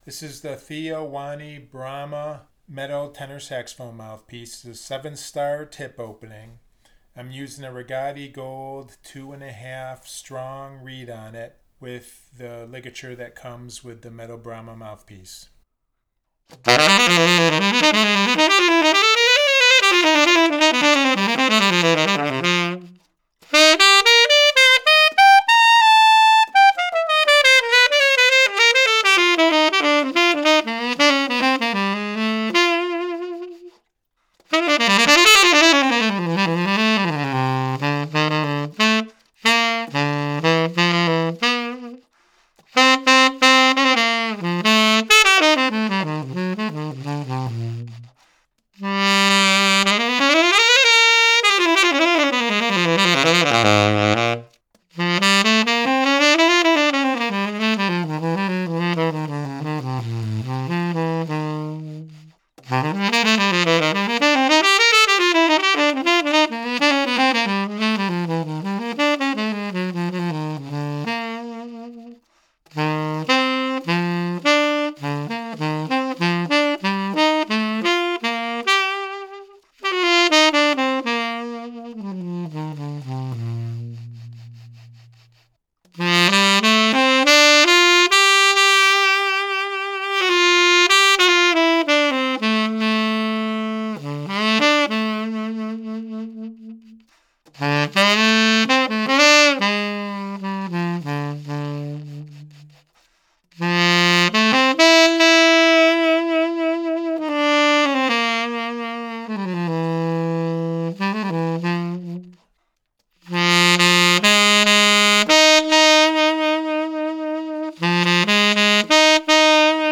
I very rarely use overtones in my sound clips when testing mouthpieces but this time I happened to play a line using overtones at the 2:51 mark of the first sound clip and I immediately noticed how in tune the overtones were with the regular notes they were paired with in that line.
The first sound clip is recorded dry and the second sound clip is the same as the first but with a slight  reverb added.
Theo Wanne Brahma Gold Tenor Saxophone Mouthpiece – Rigotti Gold 2 1/2 Strong Reed-No Effects